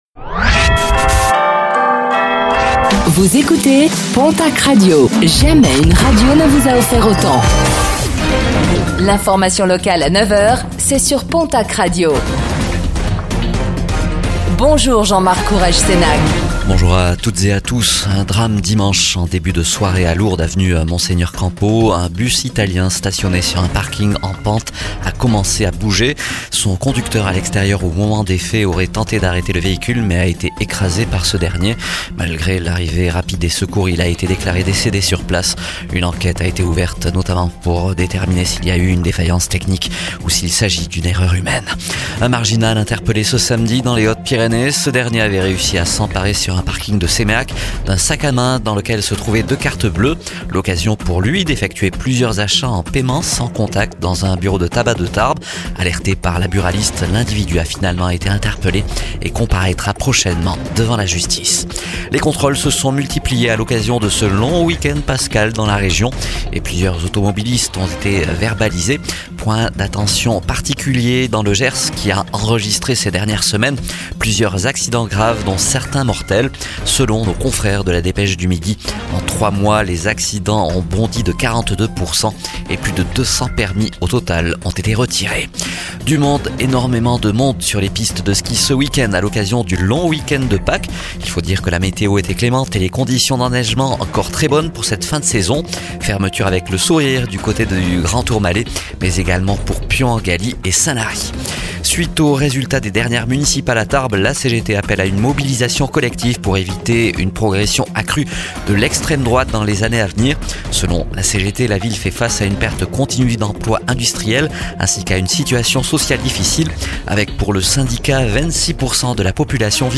Infos | Mardi 07 avril 2026